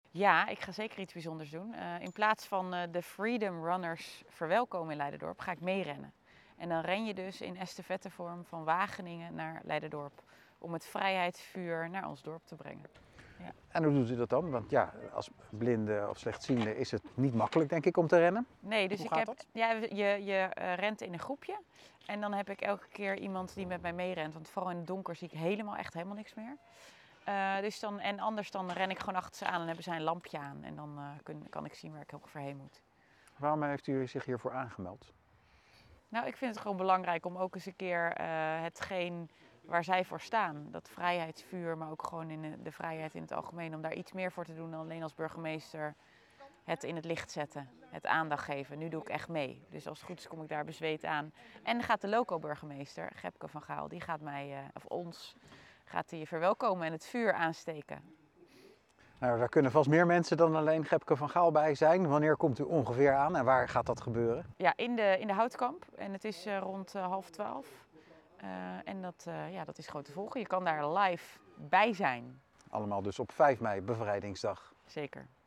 Burgemeester Tjarda Struik in gesprek met verslaggever